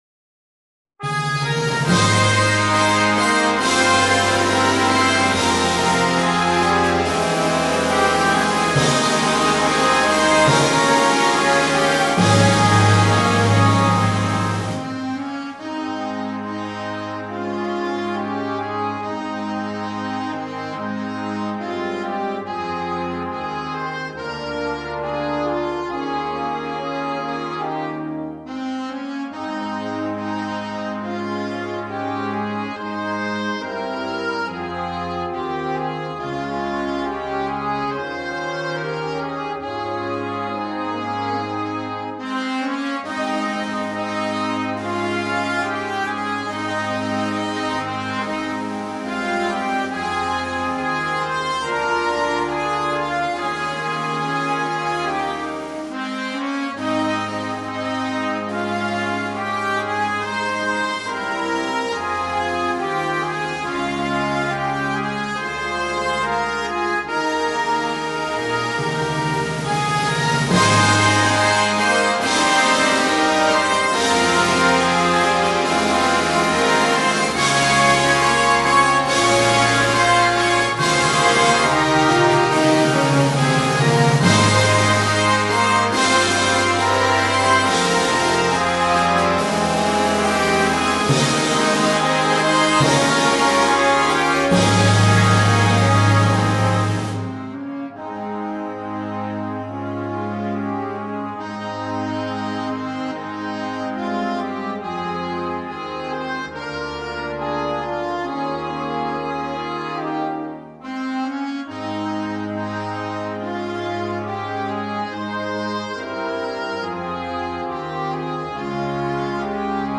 Inno religioso